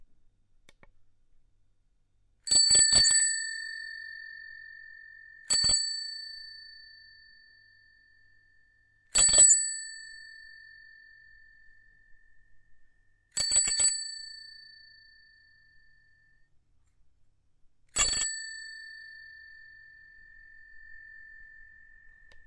自行车环形铃
描述：自行车铃
声道立体声